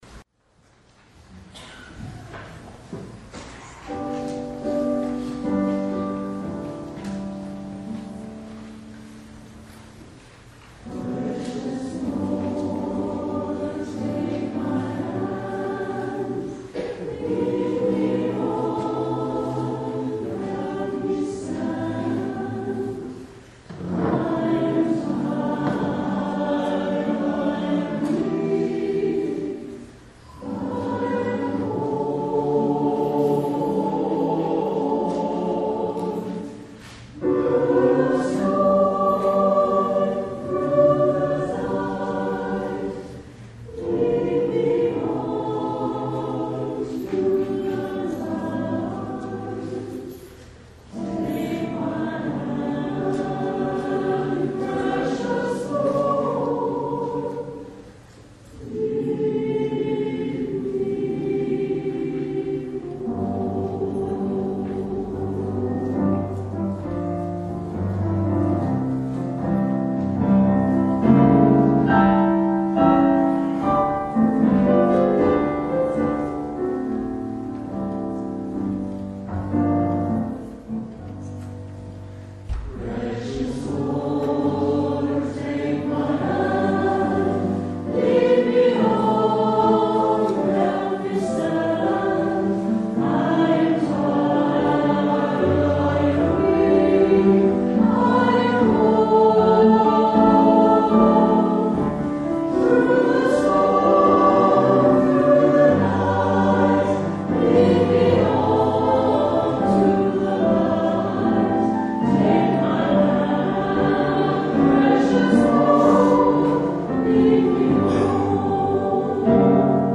Traditional Choir
Here are some wonderful songs sung by our church choir.